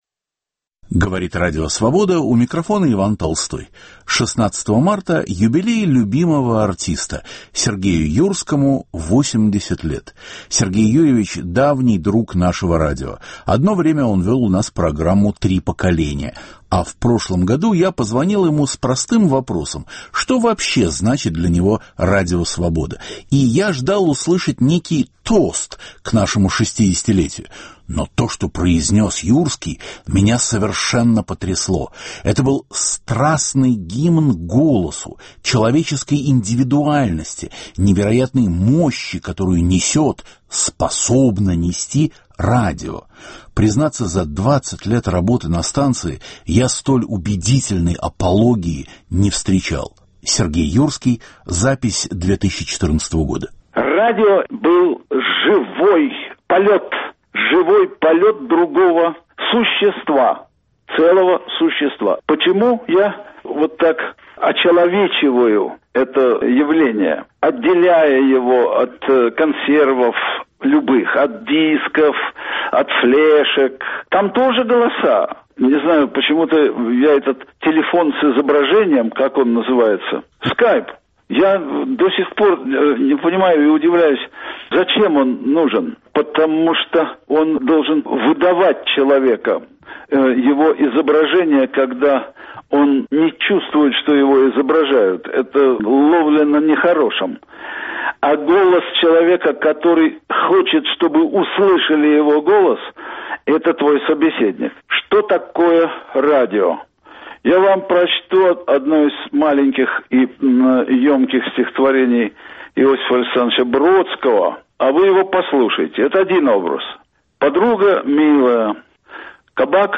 Сегодня – размышления Юрского о театре, кино, политике и обществе. Записи из архива Свободы.